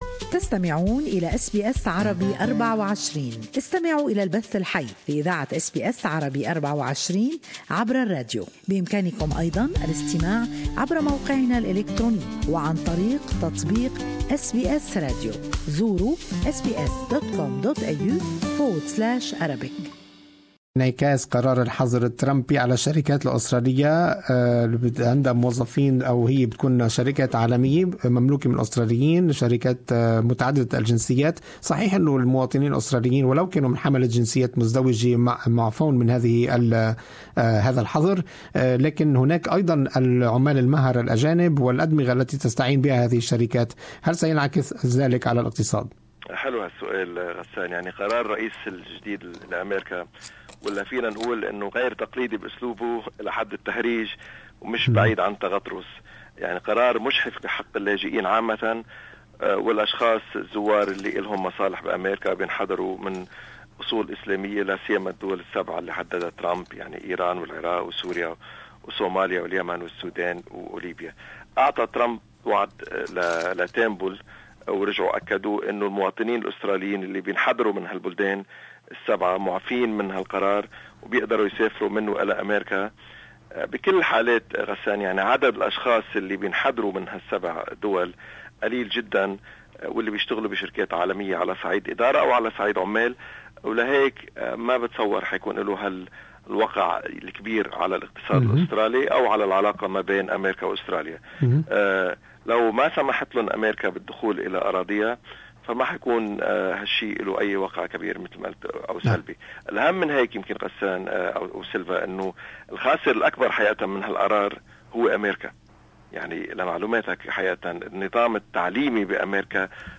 التقرير الإقتصادي: القرار الترامبي لا يؤثر على إقتصاد أستراليا